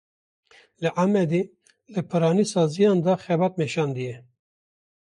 Pronounced as (IPA) /xɛˈbɑːt/